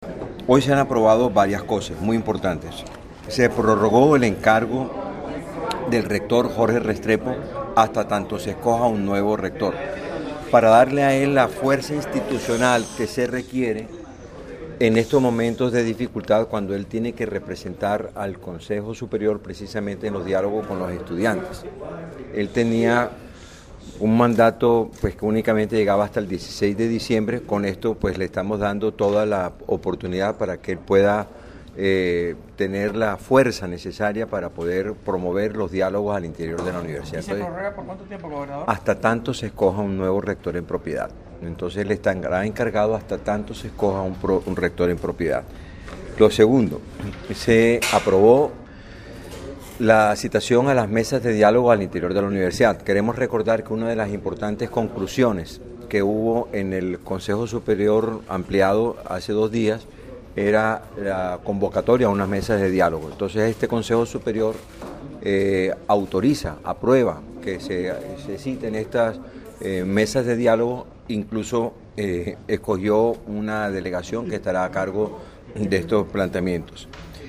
El gobernador Eduardo Verano, al finalizar la reunión llegó hasta la puerta de la Gobernación, en donde esperaba un numeroso grupo de estudiantes con quienes habló para explicarles los alcances de las medidas adoptadas, entre ellas reafirmar que el rector Carlos Prasca no regresa.
VOZ-GOBERANDOR-VERANO-CONSEJO-SUPERIOR.mp3